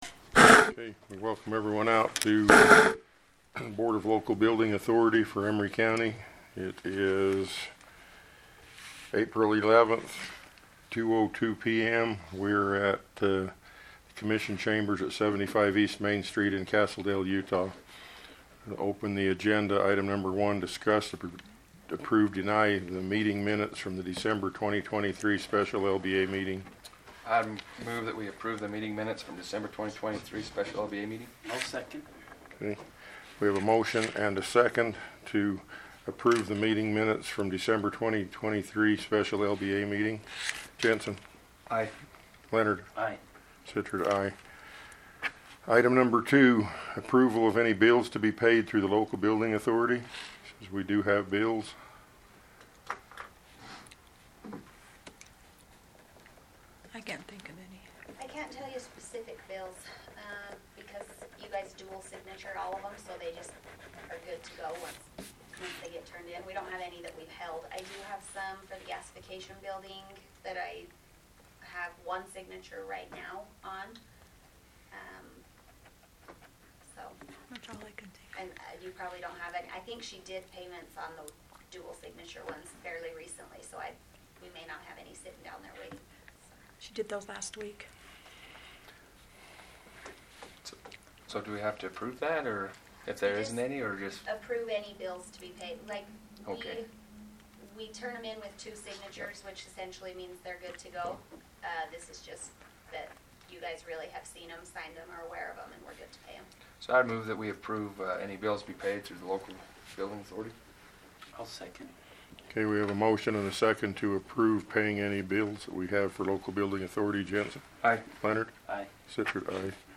Local Building Authority of Emery County Board Meeting
Notice, Meeting
75 E Main Street
Castle Dale, UT 84513